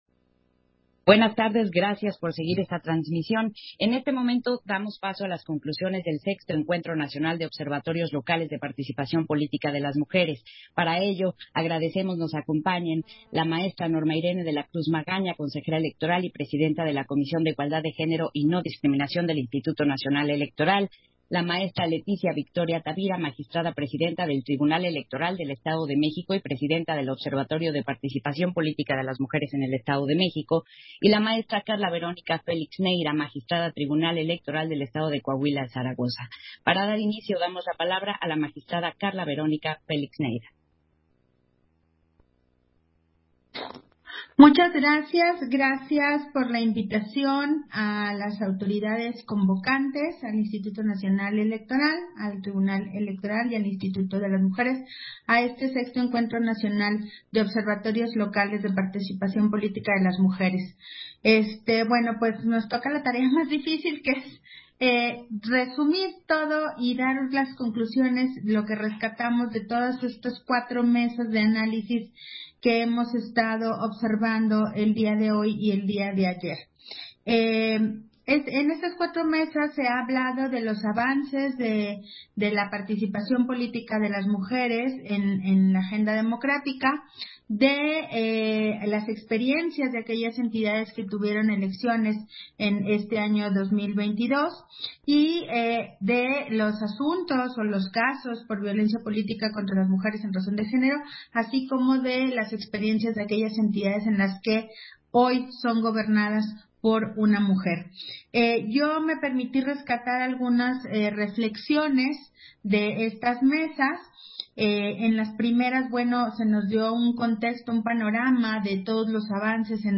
111022_AUDIO_-CONCLUSIONES-VI-ENCUENTRO-NACIONAL-DE-OBSERVATORIOS-LOCALES-DE-PARTICIPACIÓN-POLÍTICA-DE-LAS-MUJERES-1